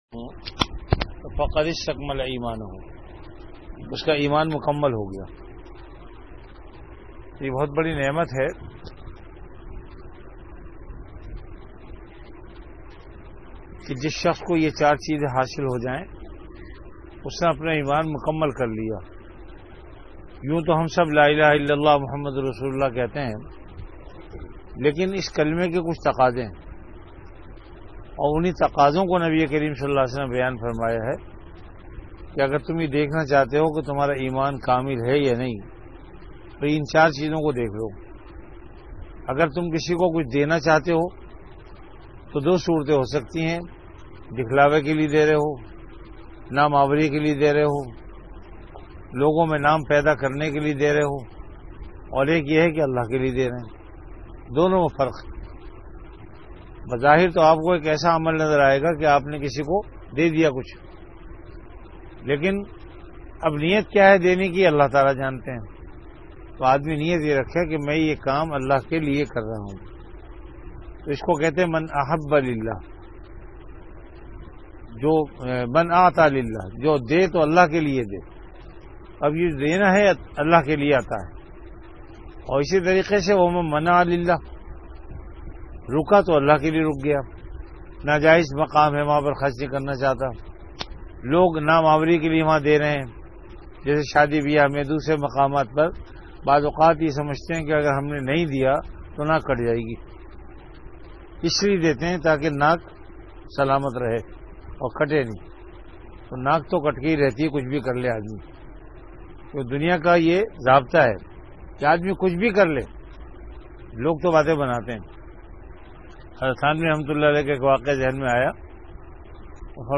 An Urdu Islamic audio lecture on Ramadan - Etikaaf Bayanat, delivered at Jamia Masjid Bait-ul-Mukkaram, Karachi.